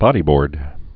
(bŏdē-bôrd)